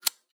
zippo_strike_fail_02.wav